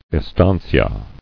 [es·tan·cia]